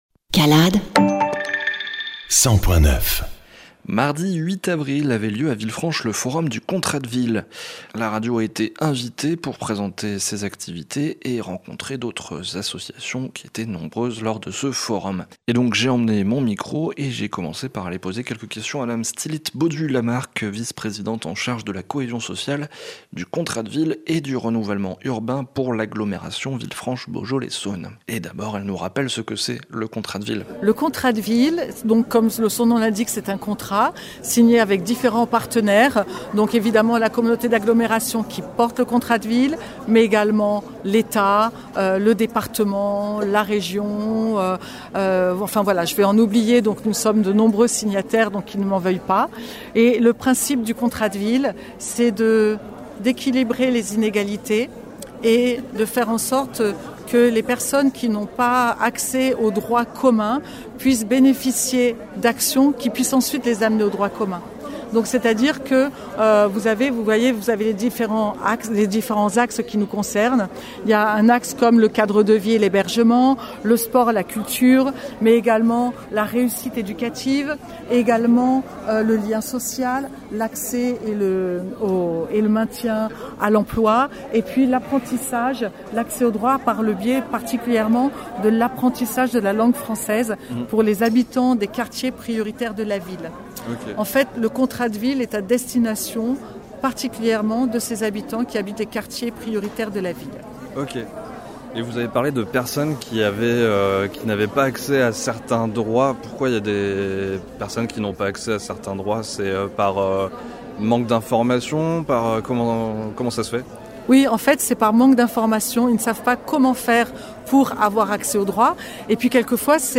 calade_INTERVIEW CALADE – 220425 Contrat de Ville